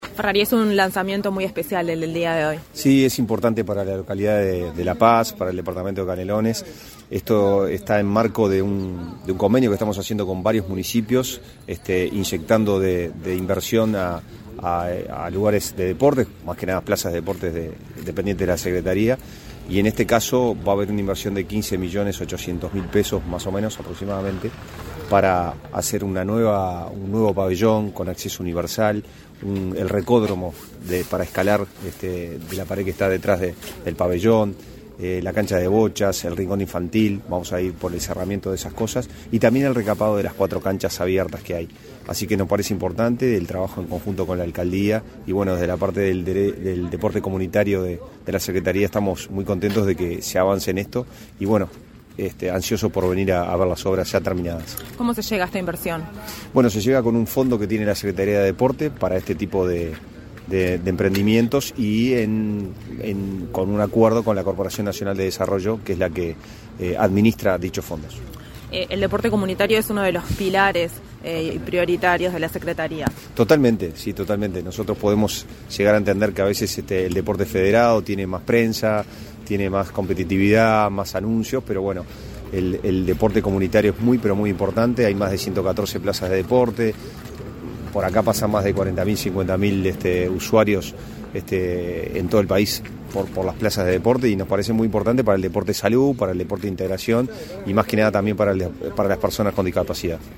Entrevista al subsecretario nacional del Deporte, Pablo Ferrari
El subsecretario Pablo Ferrari, en entrevista con Comunicación Presidencial, detalló el trabajo planificado.